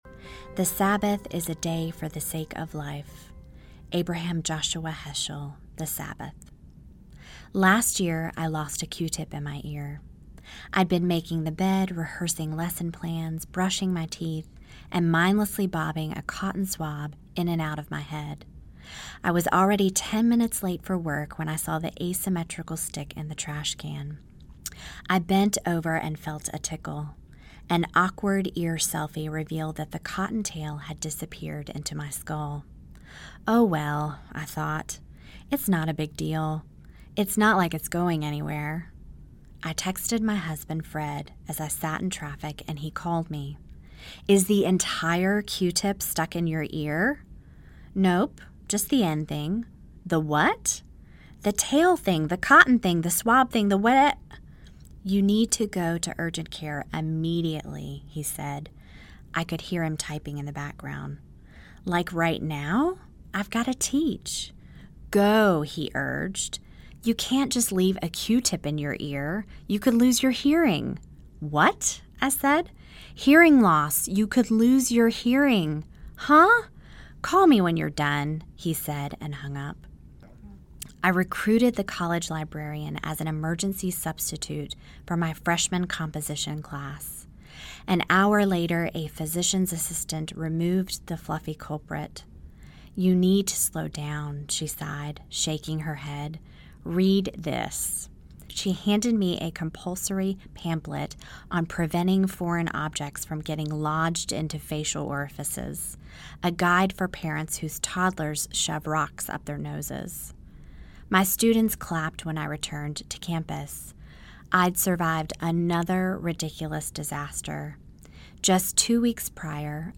For Sabbath’s Sake Audiobook